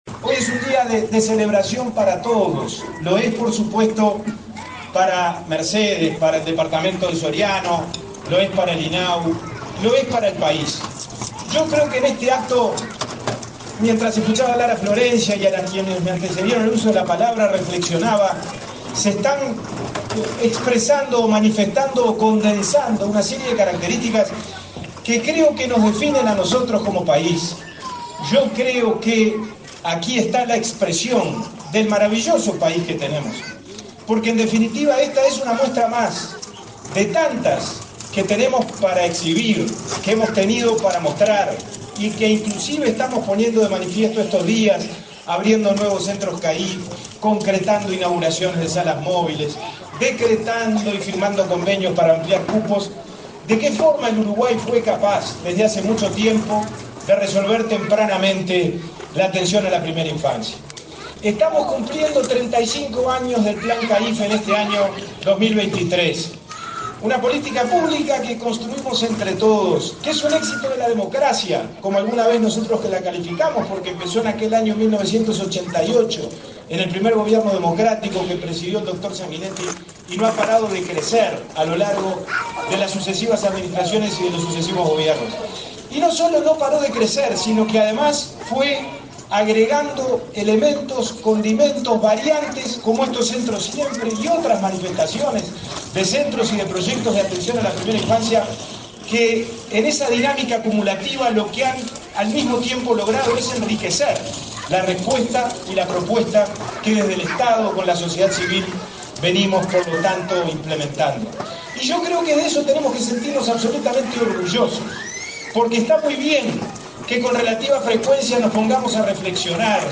Palabra de autoridades en acto en Soriano
Palabra de autoridades en acto en Soriano 28/09/2023 Compartir Facebook X Copiar enlace WhatsApp LinkedIn El presidente del INAU, Pablo Abdala, y el secretario de Presidencia, Álvaro Delgado, participaron en Soriano, el miércoles 27, de la inauguración del centro de cuidados en el Cub Social y Deportivo, Asencio de Mercedes.